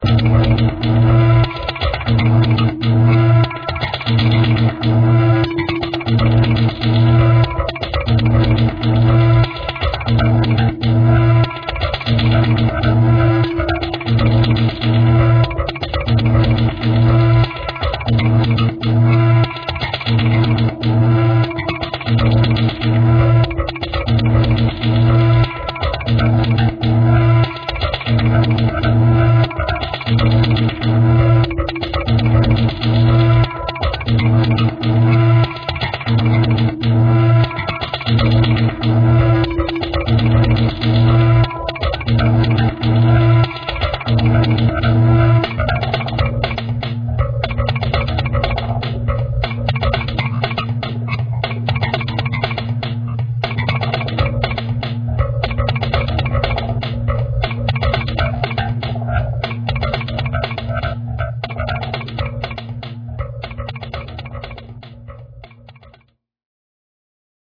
Roaylty Free Music for use in any type of